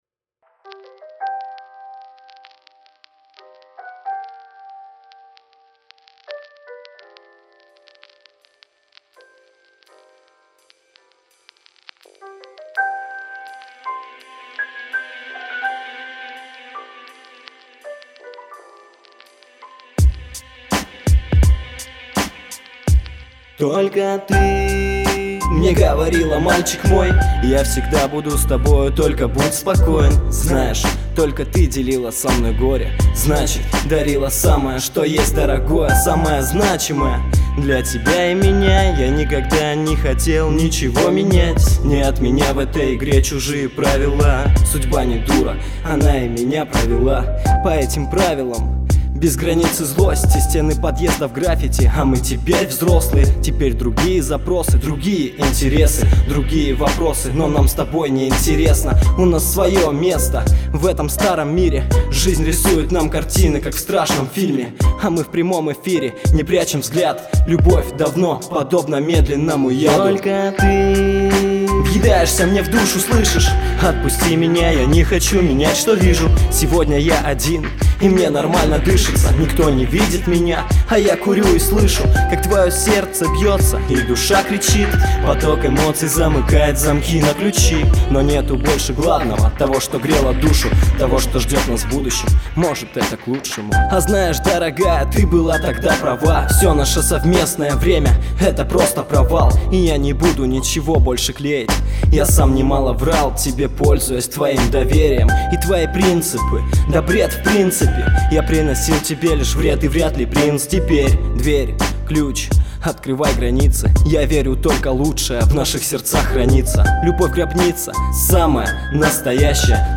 любительский рэп-вокал
Рэп